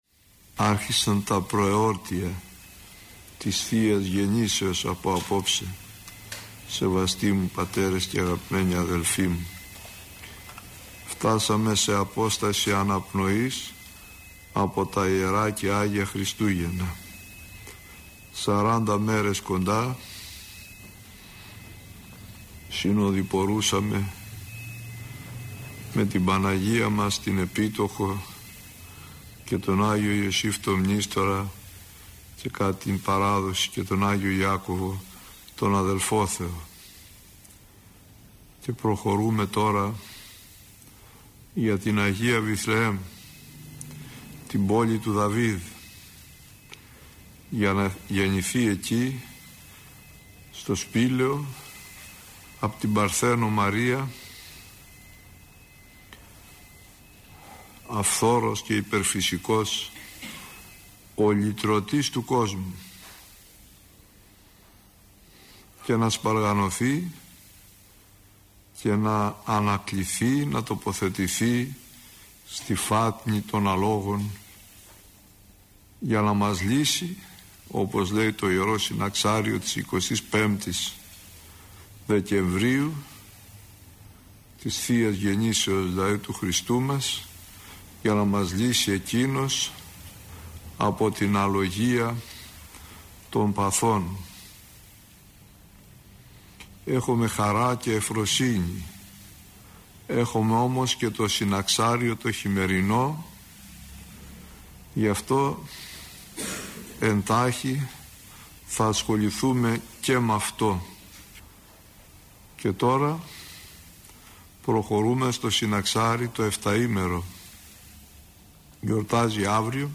Η εν λόγω ομιλία αναμεταδόθηκε από τον ραδιοσταθμό της Πειραϊκής Εκκλησίας.